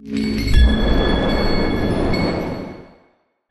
abjuration-magic-sign-rune-intro.ogg